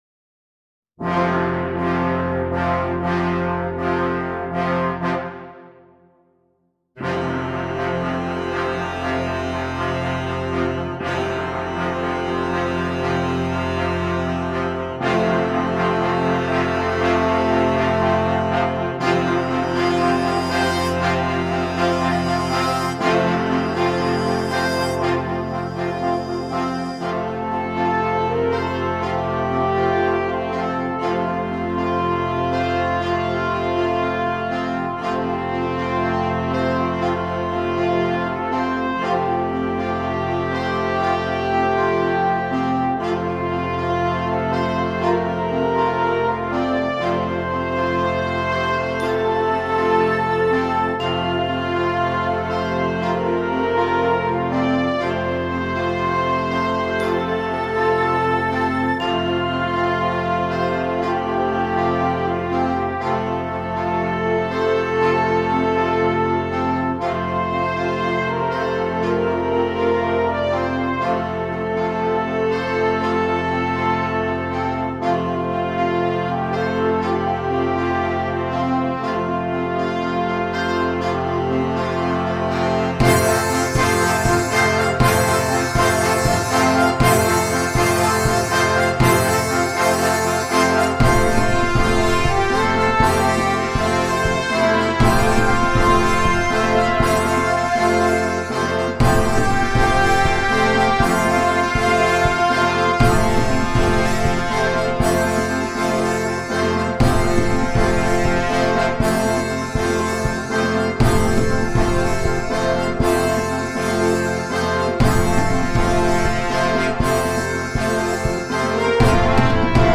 Besetzung: Alphorn Solo in F & Concert Band